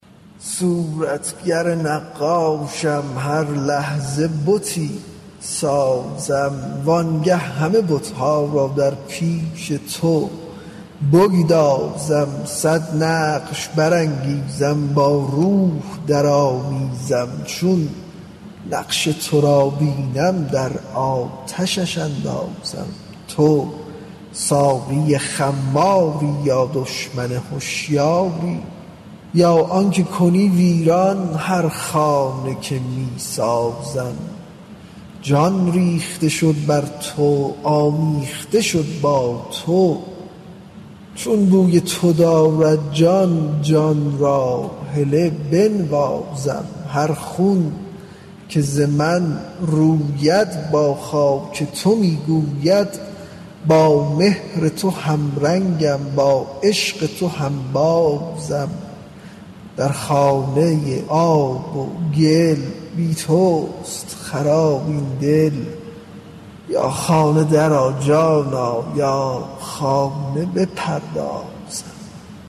غزل شمارهٔ ۱۴۶۲ به خوانش